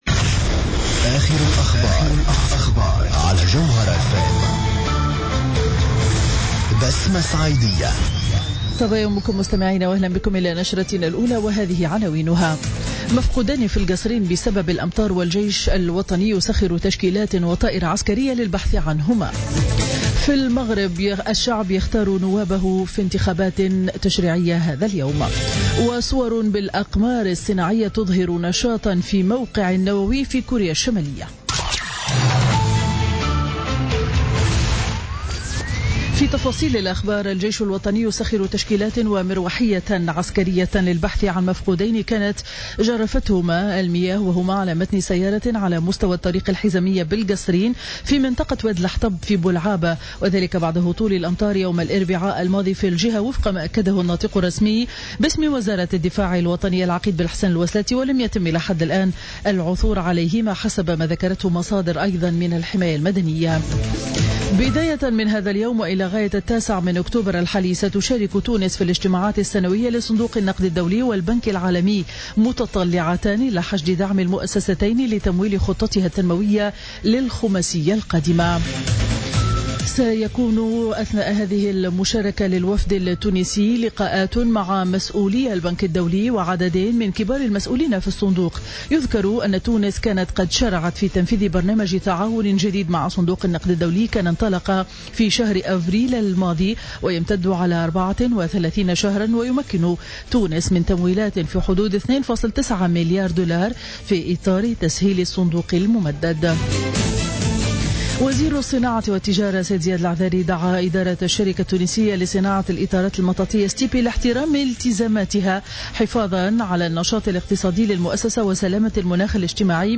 نشرة أخبار السابعة صباحا ليوم الجمعة 7 أكتوبر 2016